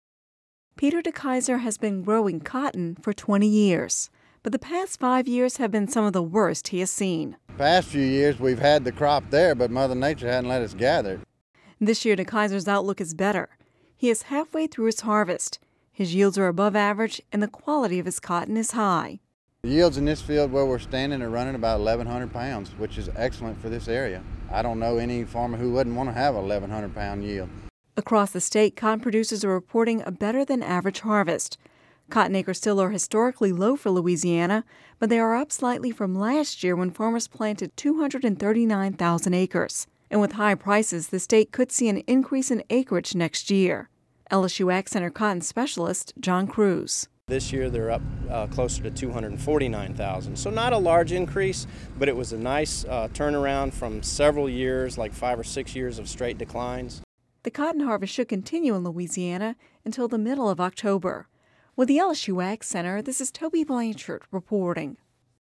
(Radio News 09/27/10)